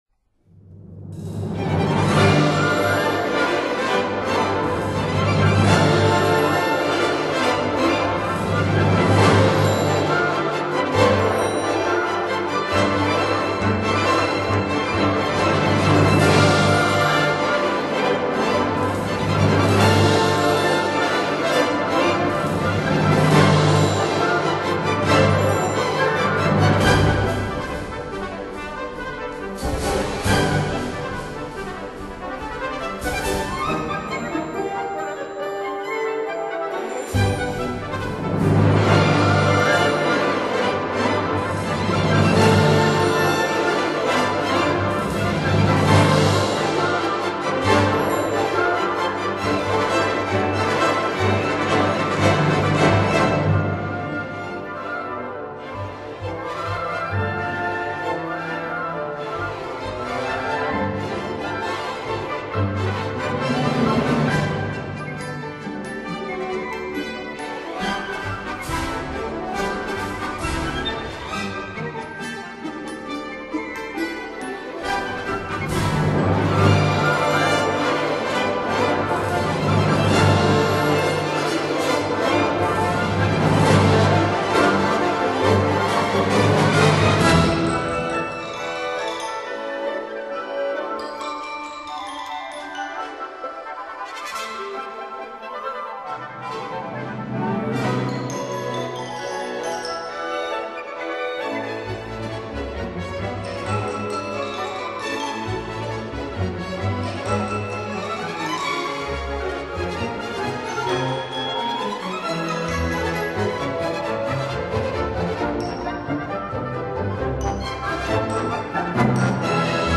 symphonic poem- 3.